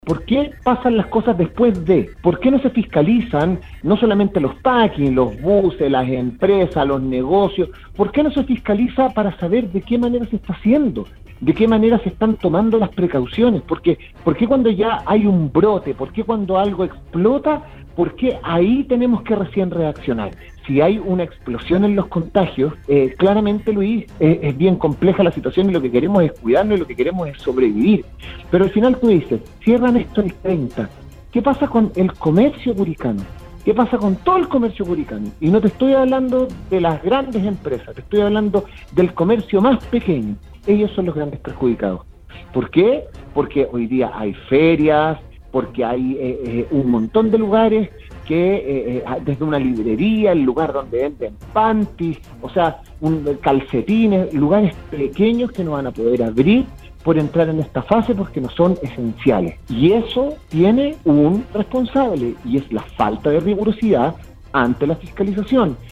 En conversación con Sala de Prensa de VLN Radio, el comunicador, Francisco Saavedra, opinó del retroceso a cuarentena para Curicó y mostró su "preocupación" por esta medida de confinamiento.